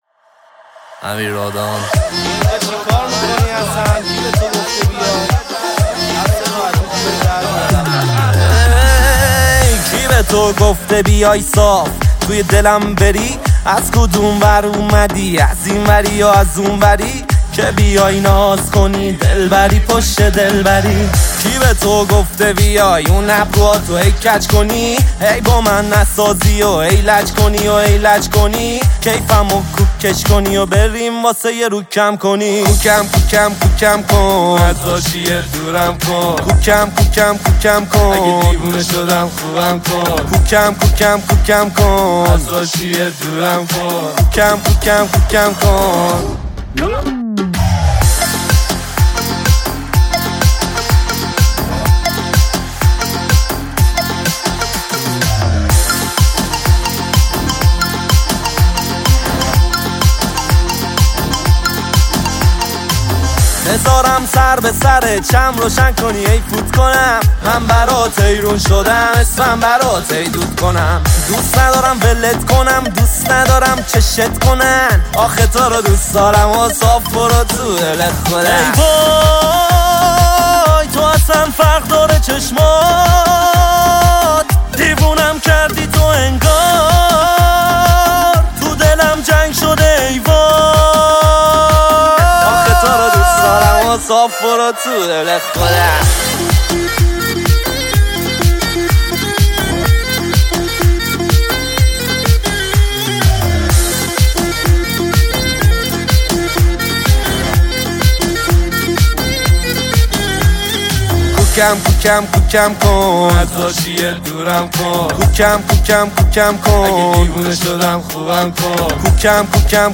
• آهنگ شاد